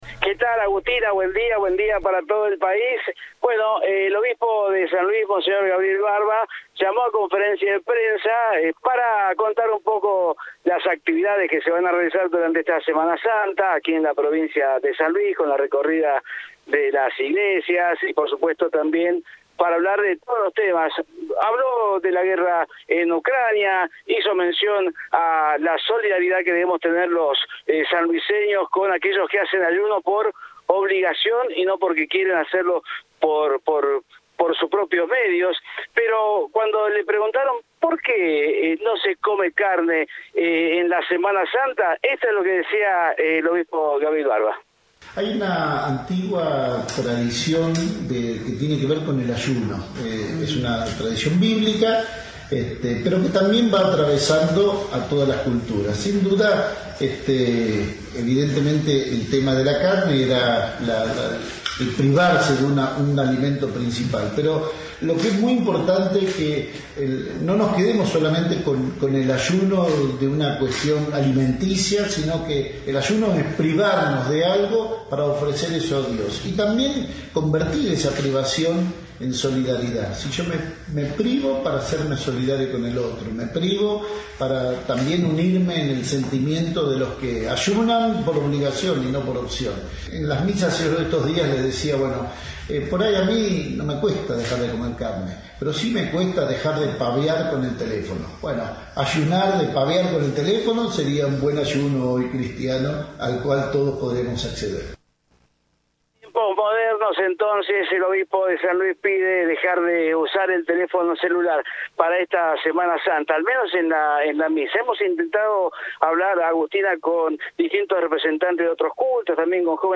El obispo de San Luis, monseñor Gabriel Barba, habló este miércoles sobre las tradiciones de la Semana Santa y se refirió particularmente al ayuno, una costumbre que implica no comer carne en el día en que se conmemora la muerte de Cristo.